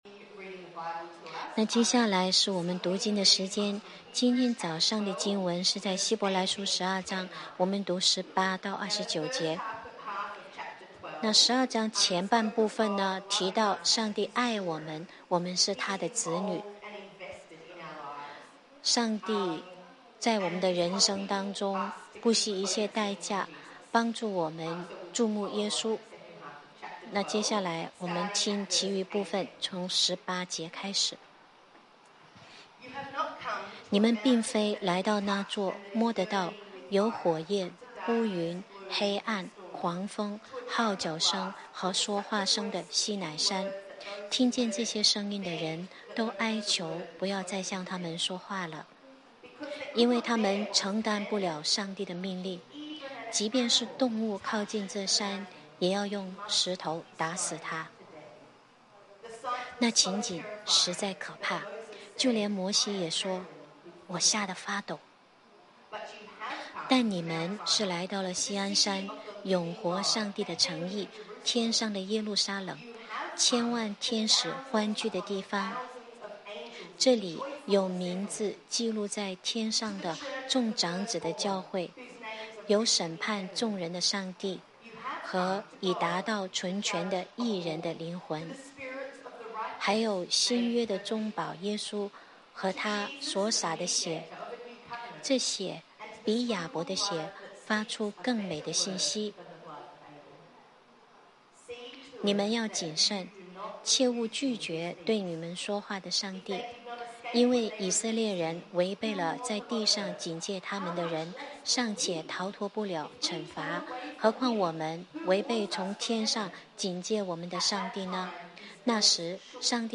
Sermons in Mandarin - The Lakes Church